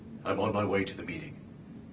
1001_IOM_SAD_XX.wav